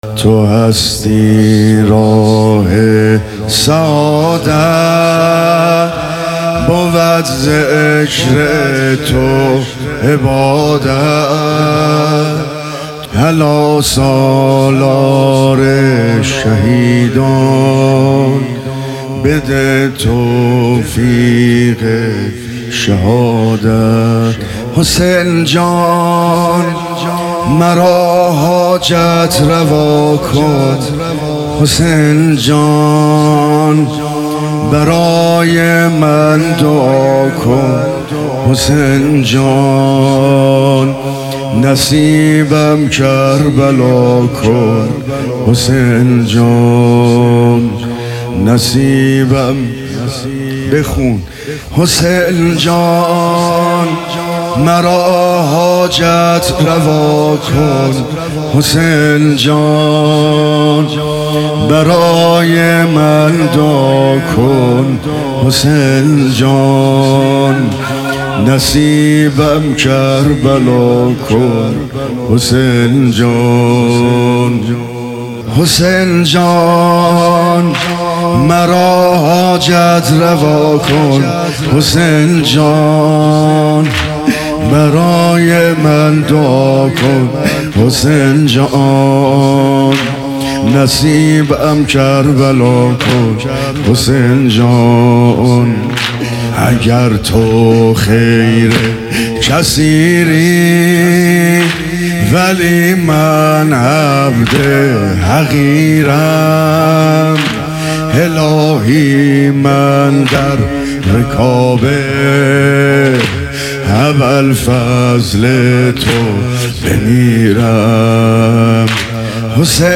حسینیه کربلا تهران